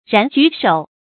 褎然舉首 注音： ㄧㄡˋ ㄖㄢˊ ㄐㄨˇ ㄕㄡˇ 讀音讀法： 意思解釋： 褎然：出眾的樣子；舉：推舉；首：第一。